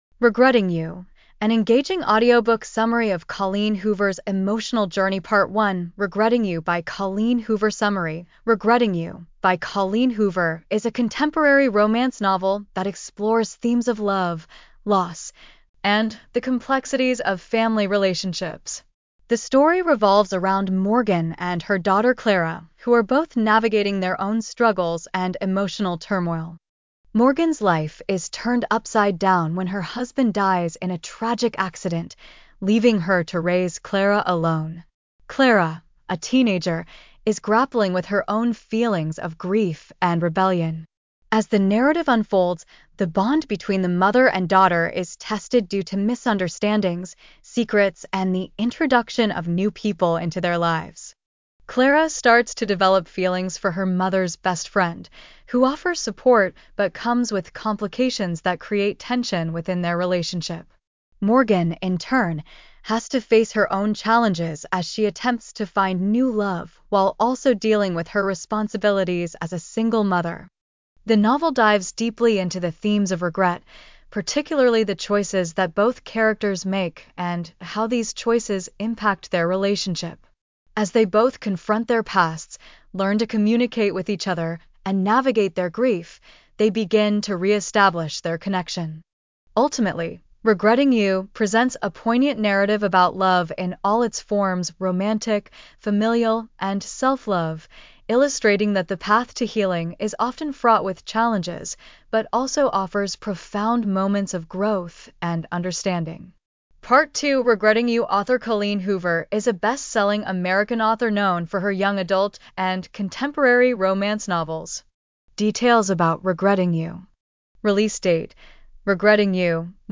Regretting You: An Engaging Audiobook Summary of Colleen Hoover's Emotional Journey